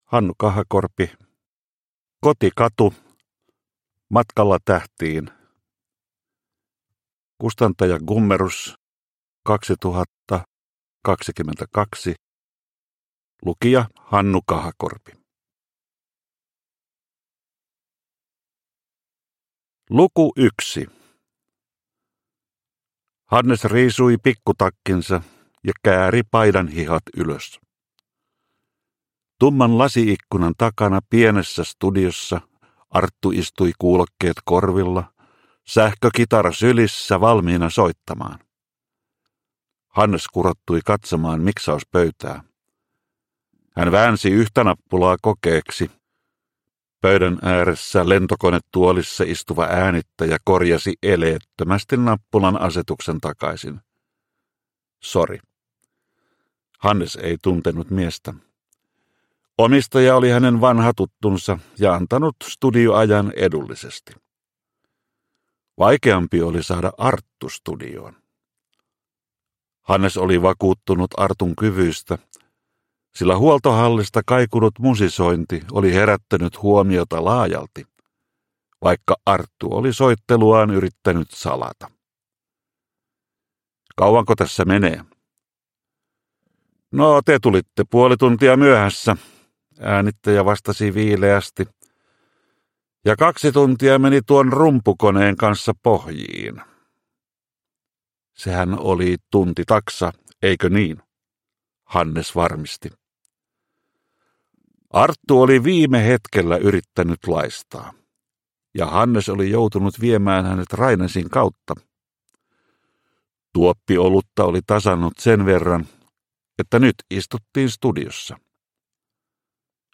Kotikatu - Matkalla tähtiin – Ljudbok – Laddas ner